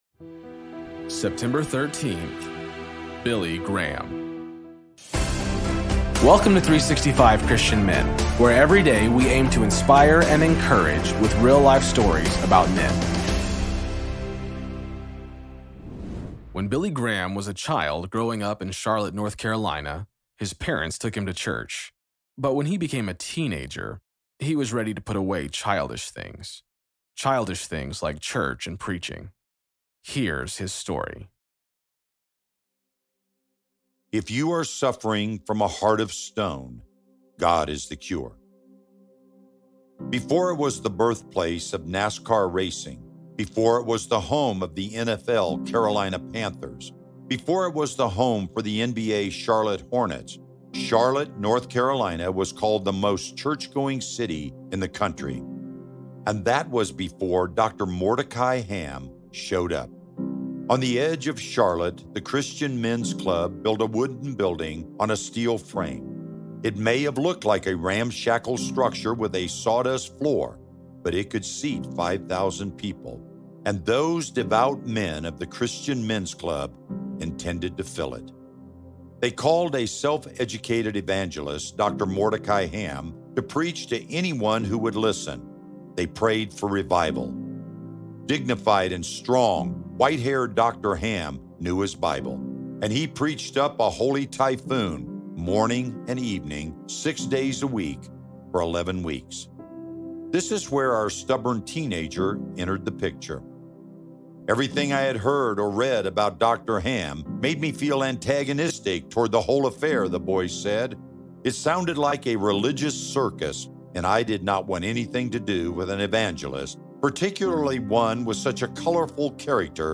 Story read by: